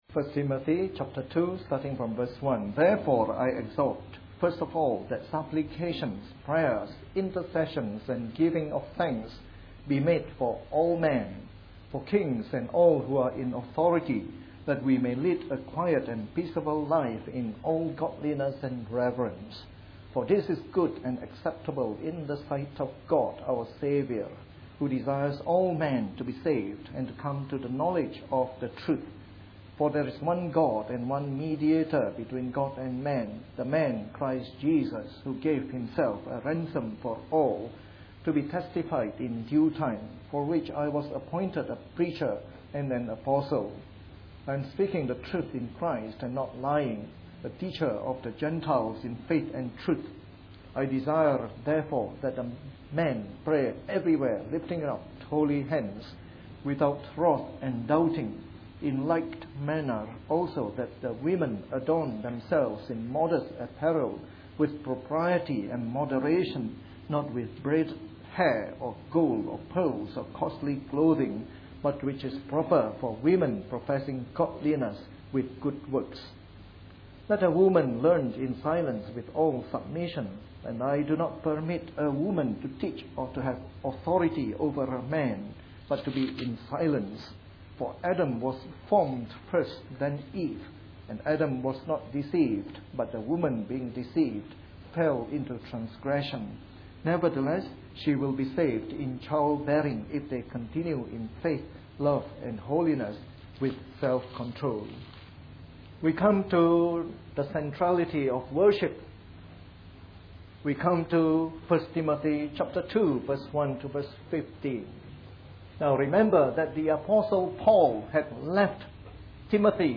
A sermon in the morning service from our new series on 1 Timothy.